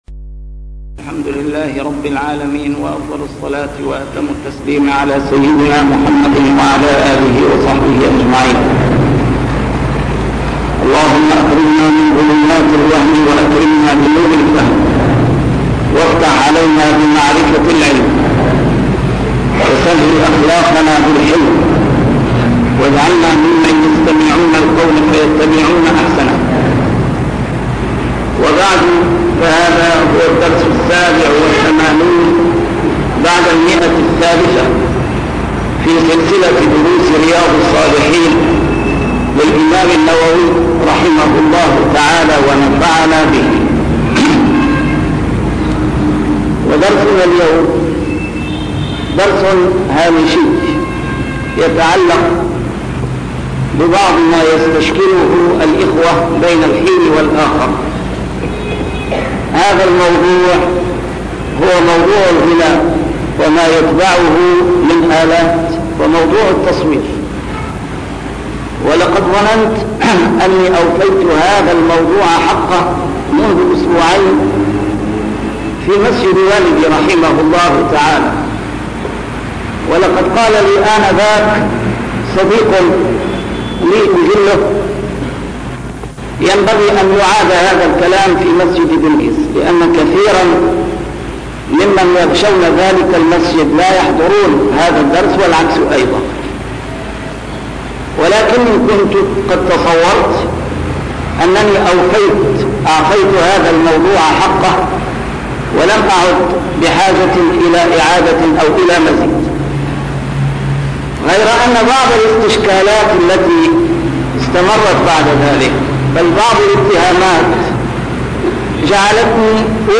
A MARTYR SCHOLAR: IMAM MUHAMMAD SAEED RAMADAN AL-BOUTI - الدروس العلمية - شرح كتاب رياض الصالحين - 387- شرح رياض الصالحين: حكم الغناء والتصوير